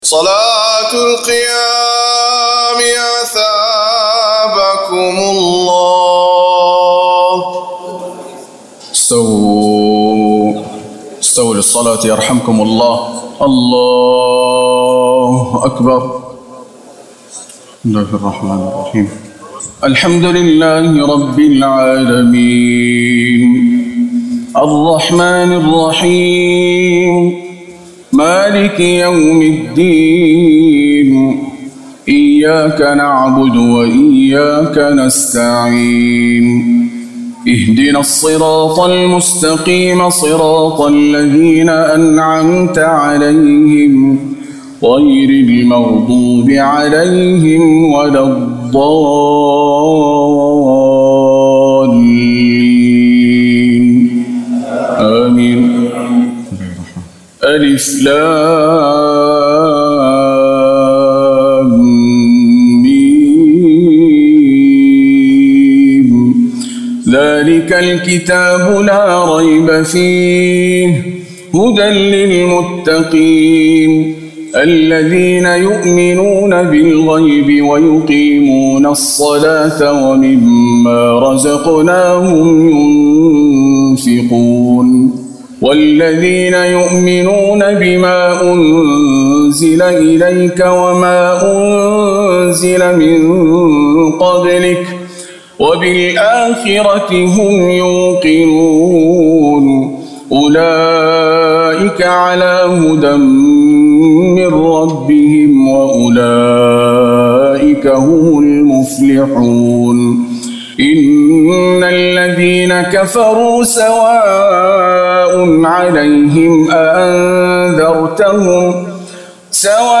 تهجد ليلة 21 رمضان 1439هـ من سورة البقرة (1-91) Tahajjud 21 st night Ramadan 1439H from Surah Al-Baqara > تراويح الحرم النبوي عام 1439 🕌 > التراويح - تلاوات الحرمين